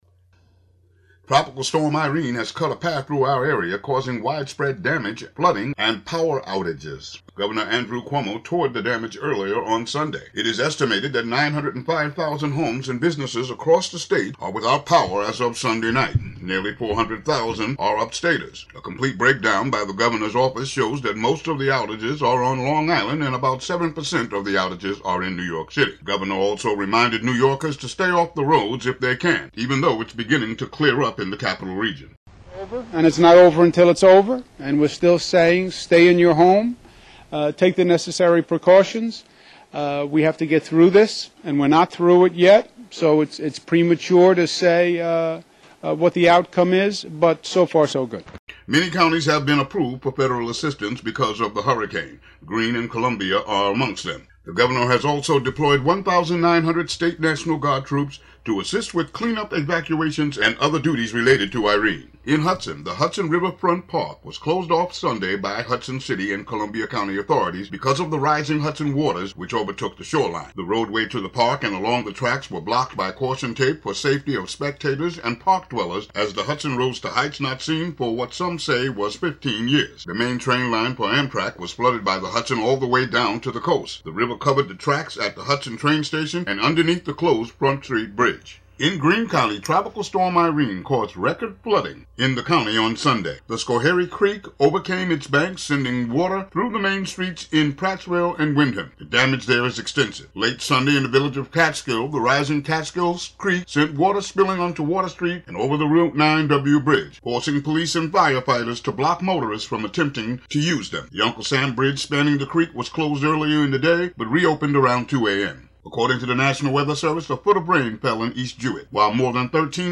Morning news coverage of Tropical Storm Irene including current road closings, outages, and relief efforts in Greene and Columbia Counties.